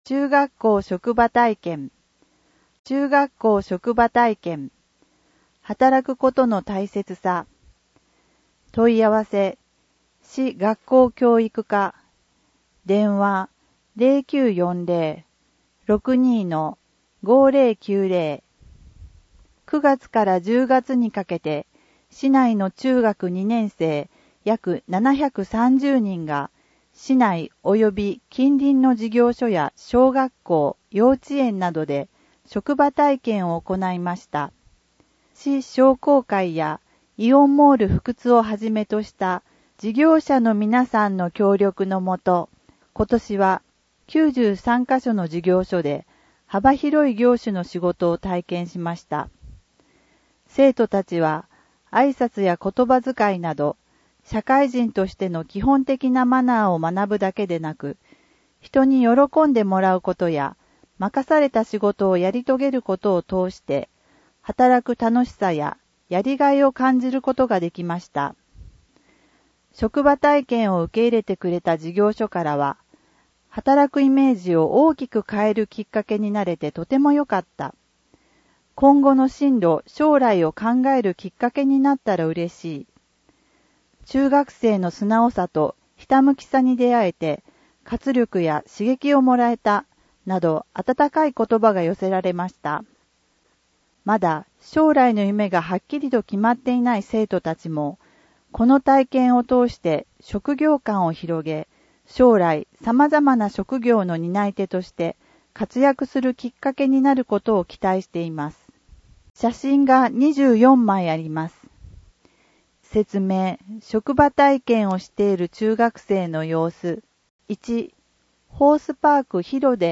音訳ボランティアふくつの皆さんが、毎号、広報ふくつを音訳してくれています。